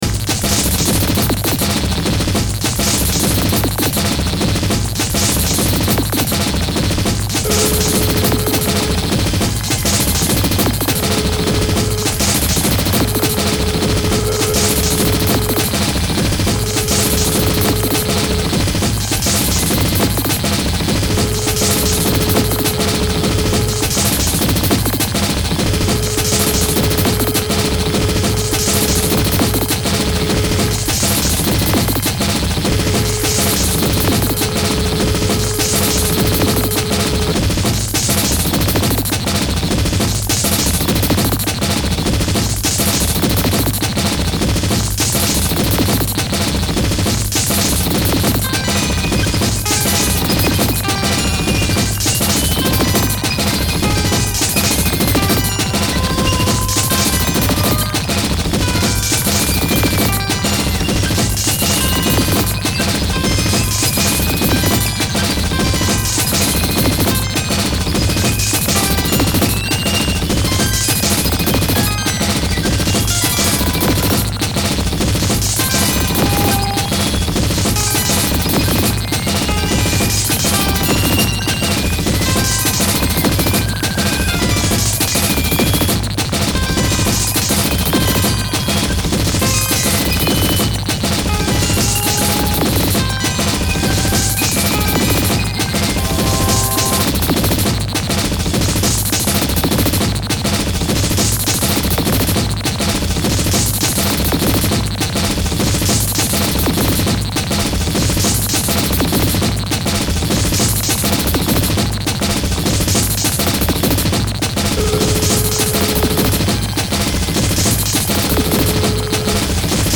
noise, experimental, electro,